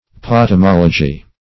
Search Result for " potamology" : The Collaborative International Dictionary of English v.0.48: Potamology \Pot`a*mol"o*gy\, n. [Gr.